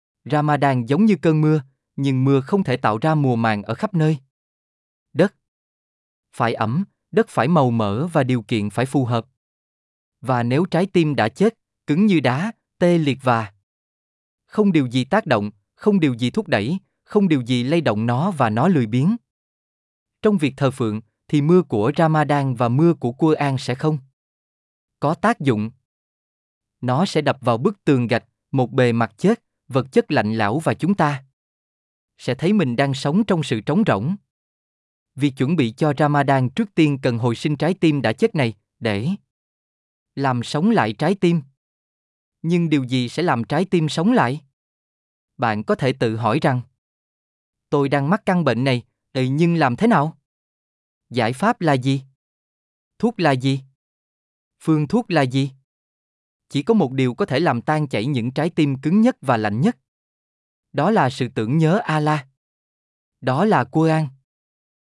صوتية مدبلجة باللغة الفيتنامية عن كيفية الاستعداد لشهر رمضان المبارك. تتناول الخطوات العملية والروحية التي يمكن للمسلم اتخاذها للتهيؤ لهذا الشهر الكريم والاستفادة القصوى من بركاته ونفحاته الإيمانية